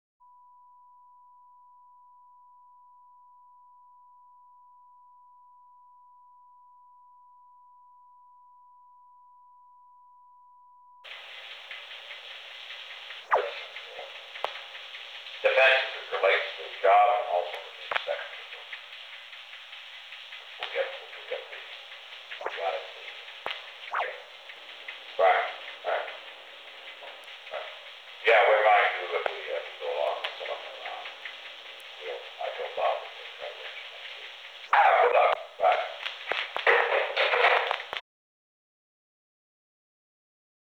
Conversation: 362-002
Recording Device: Old Executive Office Building
On August 4, 1972, President Richard M. Nixon and Jeb Stuart Magruder met in the President's office in the Old Executive Office Building from 2:23 pm to 2:28 pm. The Old Executive Office Building taping system captured this recording, which is known as Conversation 362-002 of the White House Tapes.
The President talked with Jeb Stuart Magruder.